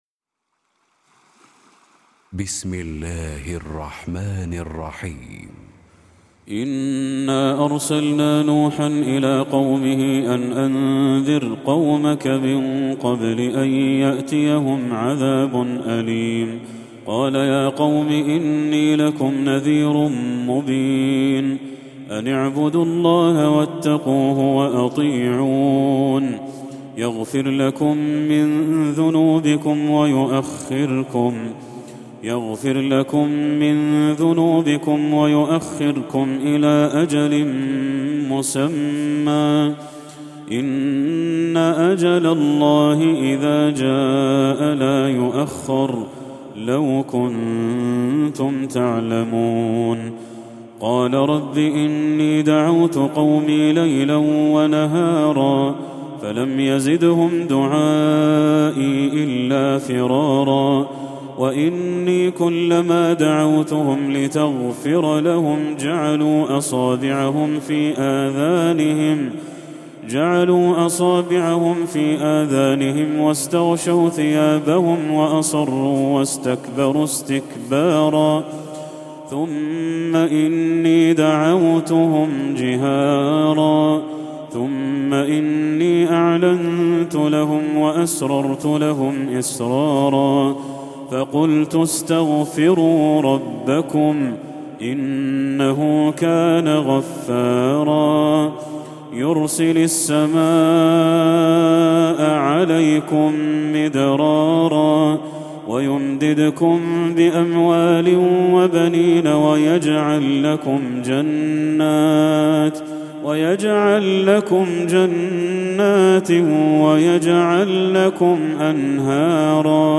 Recitime